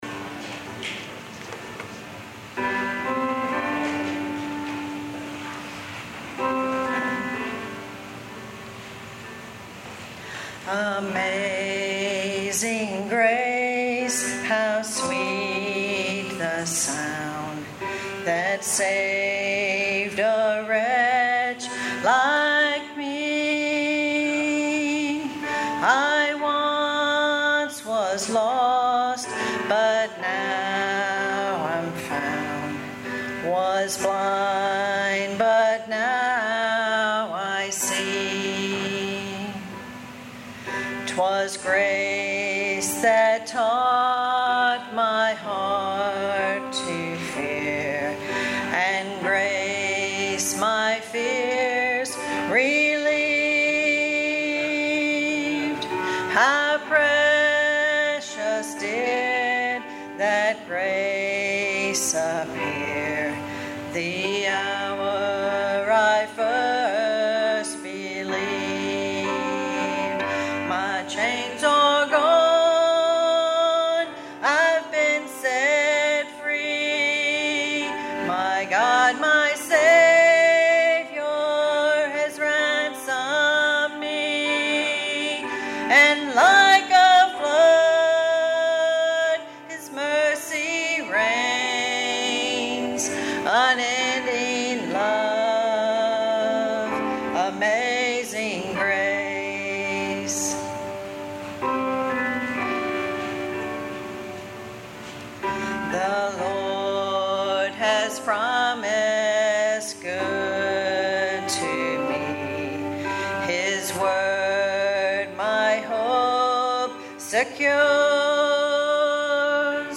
This sermon focuses on Grace in action. The text is from Ephesians 2:8-10.